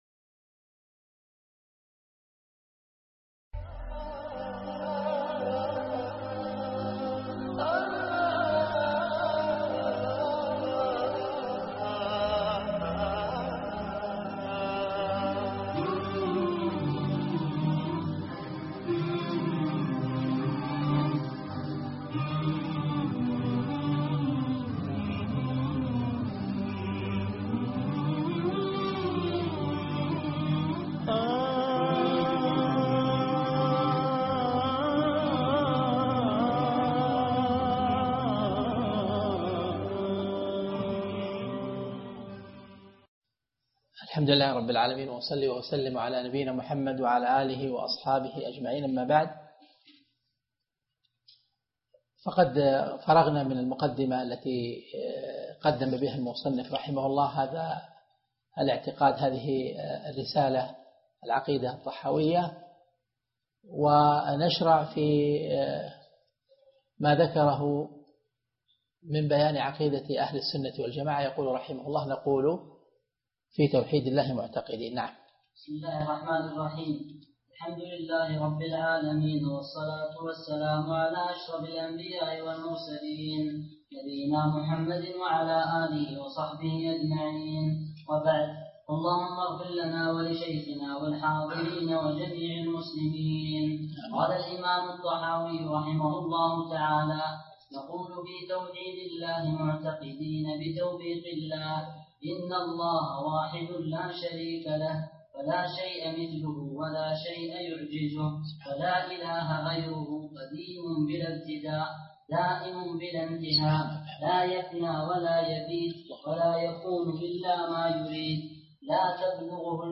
الدرس (2) من شرح العقيدة الطحاوية